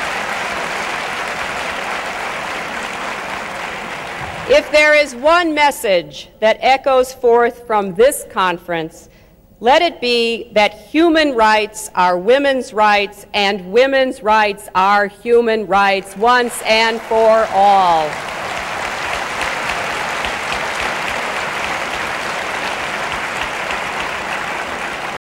클린턴이 "만약 이 회의에서 메아리치는 메시지가 있다면, 그것은 인권은 여성의 권리이고, 여성의 권리는 인권이라는 것을 영원히 선언하는 것입니다."라고 선언하는 오디오 파일
1995년 9월 5일, 힐러리 클린턴은 베이징에서 열린 제4차 세계여성회의에서 "여성의 권리는 인권이다"라는 유명한 연설을 했다.
1995년 9월 5일, 클린턴은 회의의 대강당에서 연설을 했다.[19]